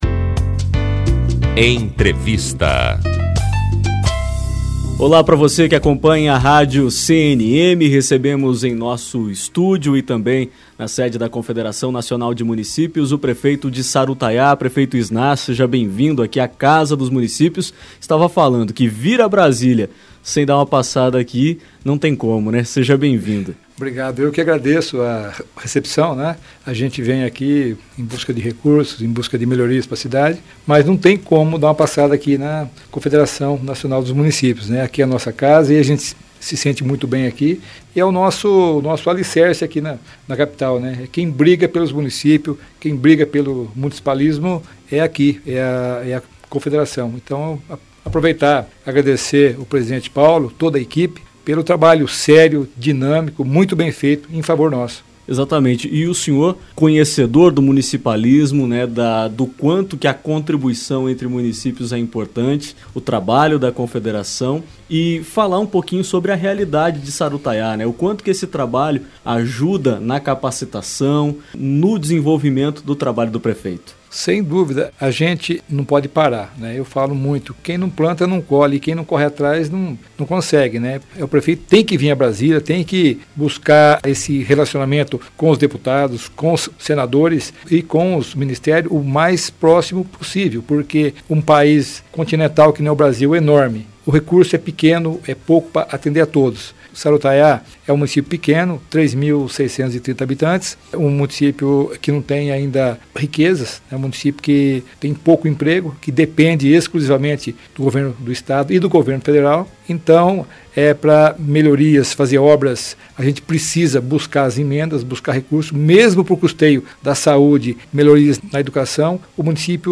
Entrevista - Prefeito Isnar Freschi Soares | Sarutaiá(SP)
Entrevista_Prefeito_Isnar_Freschi_Soares_Sarutai_SP.mp3